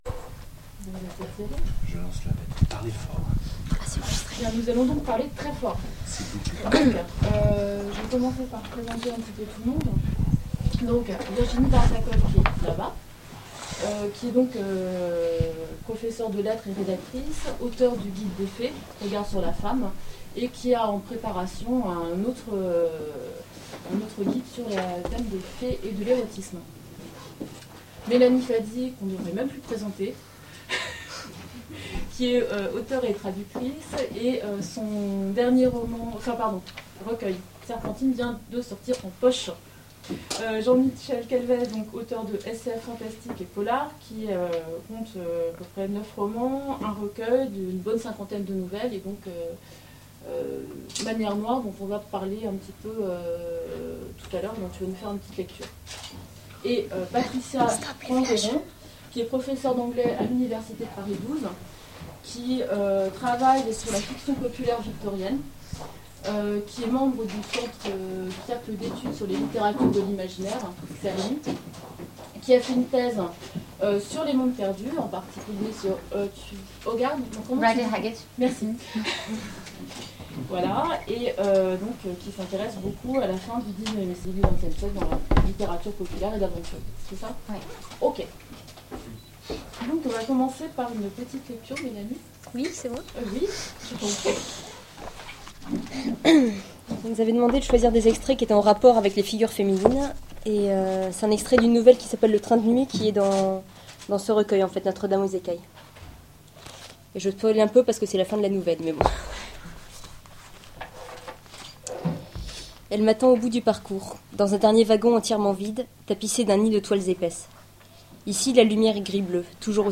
Conférence : Figures féminines dans l'imaginaire
Voici l'enregistrement de la conférence organisée au Kata Bar par Muses et Merveilles : Figures féminines dans l'imaginaire en mars 2010 (attention, le son n'est pas de très bonne qualité) Télécharger le MP3